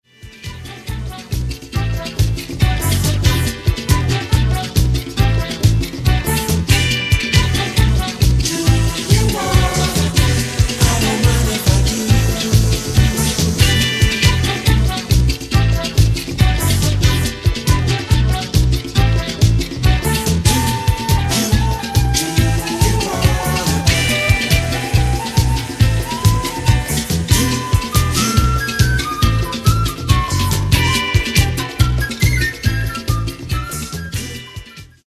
Genre:   Latin Disco